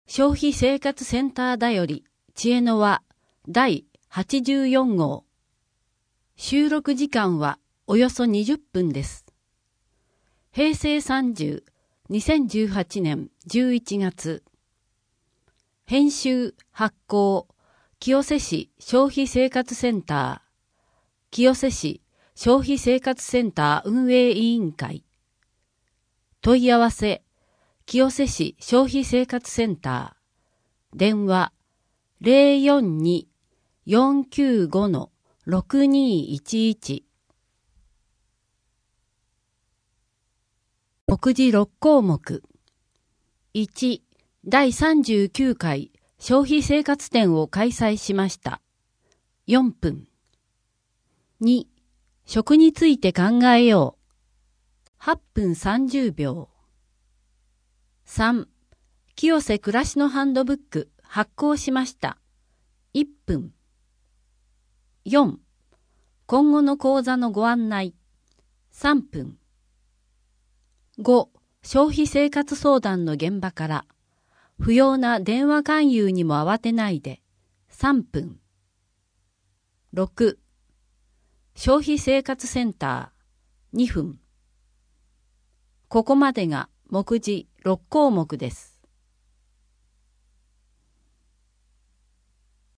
2・3面 「食」について 今後の講座のご案内 4面 消費生活相談の現場から 不要な電話勧誘にもあわてないで「無料点検」「保険金が使える」に注意 声の広報 声の広報は清瀬市公共刊行物音訳機関が制作しています。